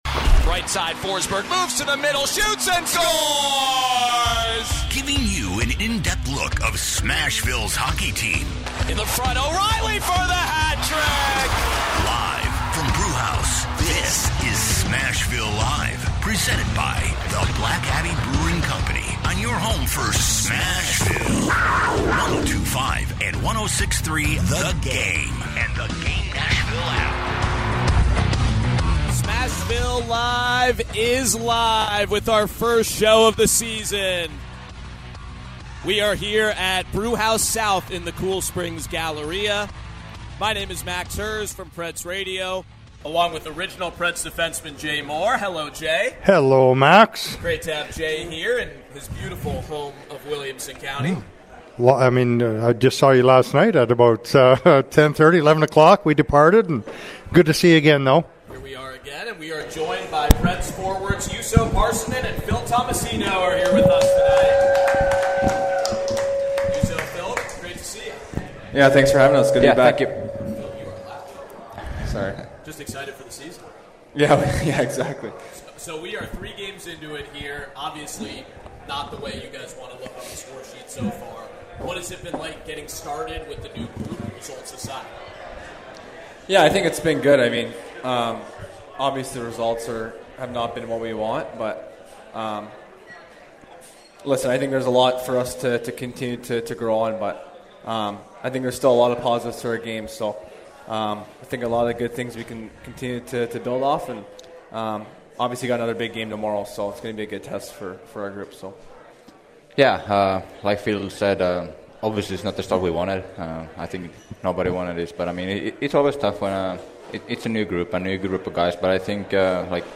live from Brewhouse for the first show of the regular season